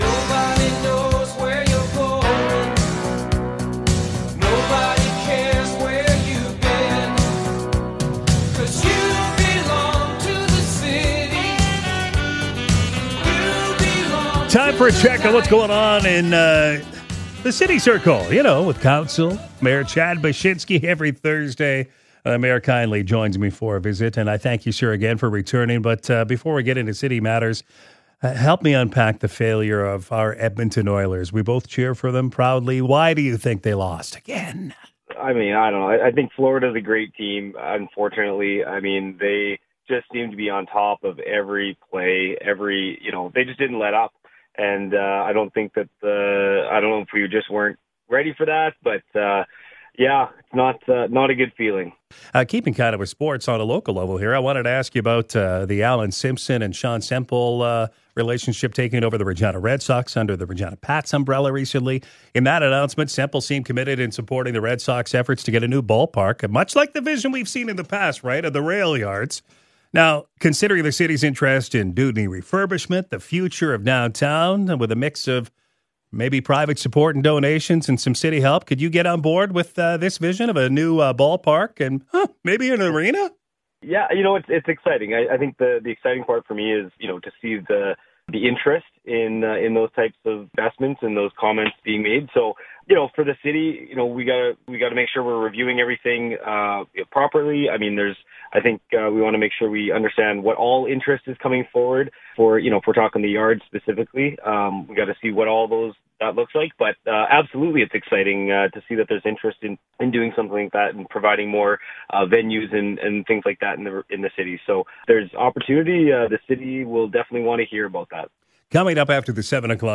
Mayor Chad Bachysnki is here for his weekly visit. With a new midway in place, will the QCX be as big as years past?